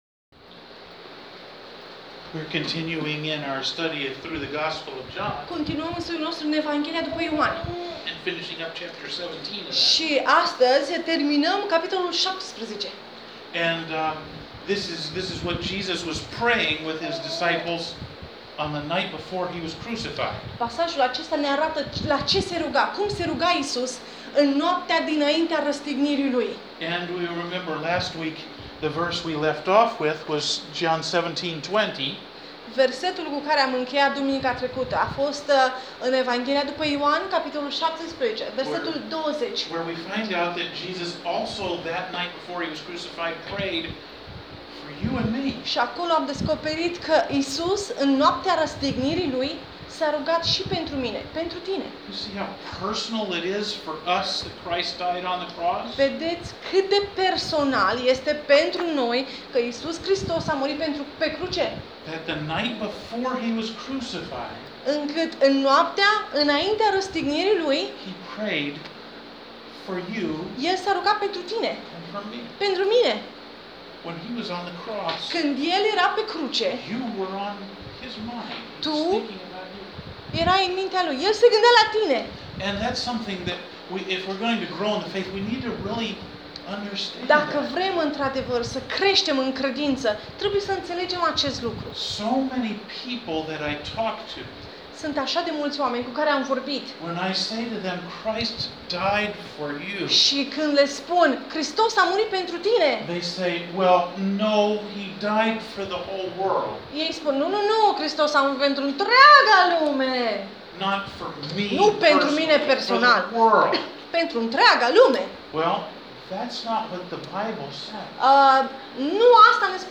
Ioan 17:20-26 sermon audio